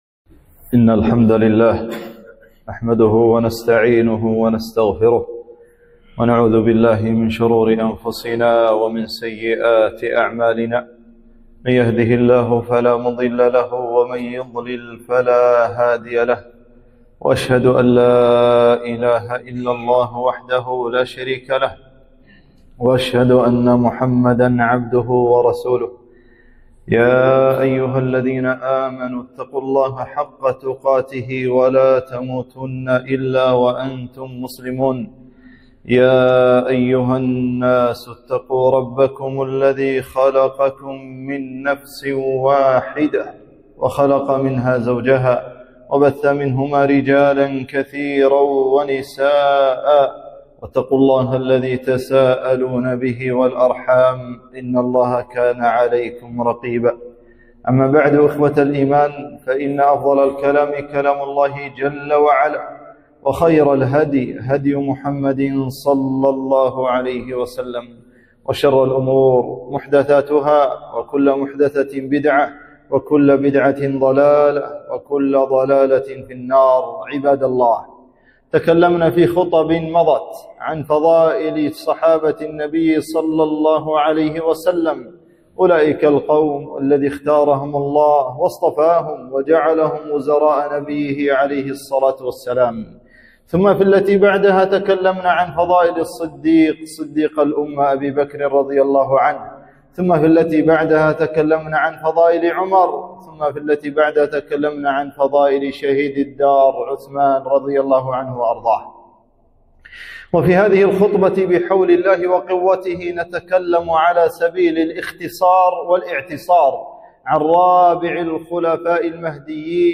خطبة - فضل الصحابي علي بن أبي طالب رضي الله عنه